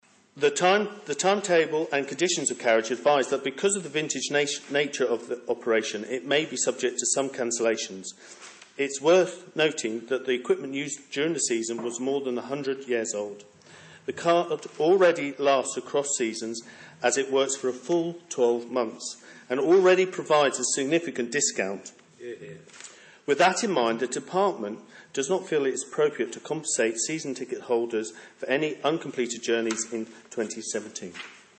Minister Ray Harmer responded to him in Tynwald last week: